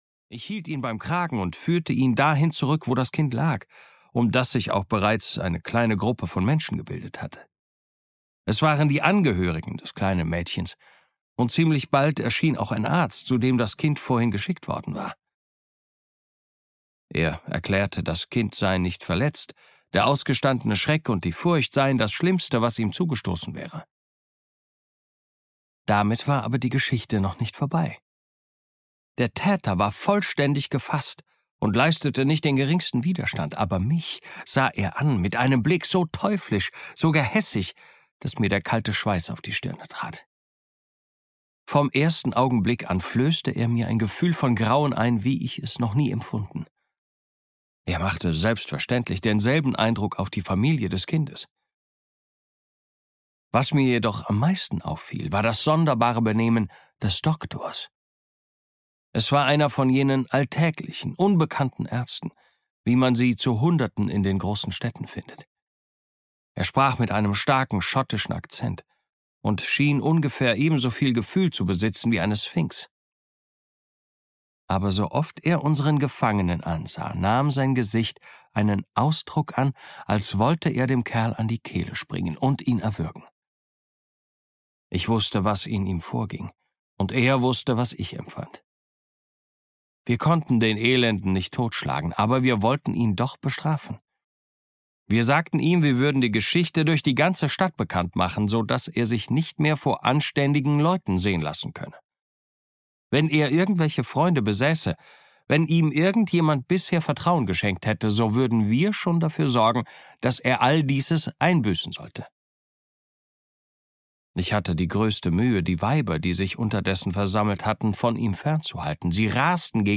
Der seltsame Fall des Dr. Jekyll und Mr. Hyde (DE) audiokniha
Ukázka z knihy
der-seltsame-fall-des-dr-jekyll-und-mr-hyde-de-audiokniha